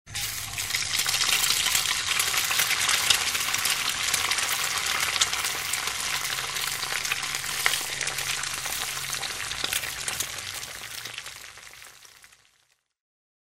Звуки жарки, гриля
Шкворчание яиц на раскаленной сковороде